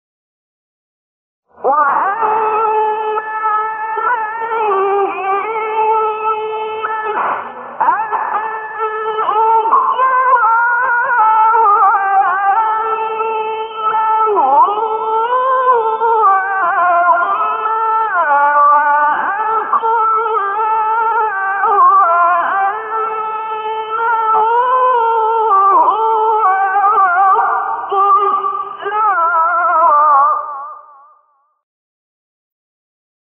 سایت قرآن کلام نورانی - نهاوند شعیشع (3).mp3